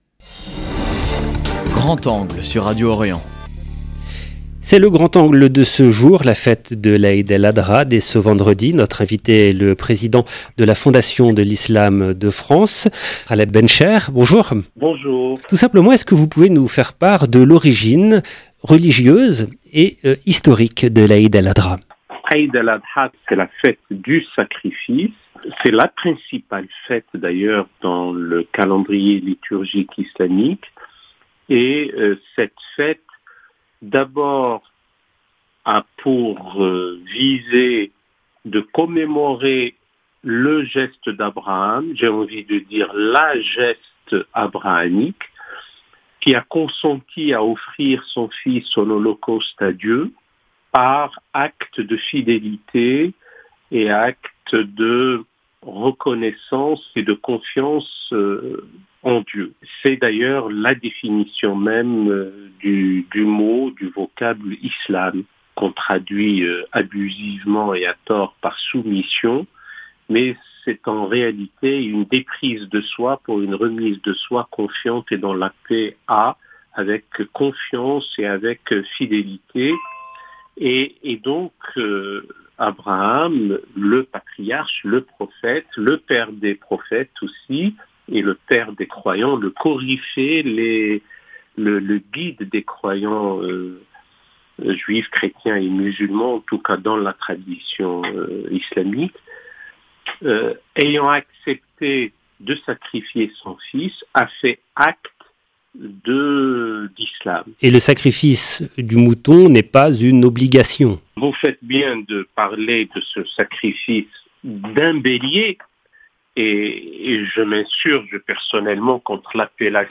Aïd El Adha dès vendredi : entretien avec Ghaleb Bencheikh, président de la Fondation de l’Islam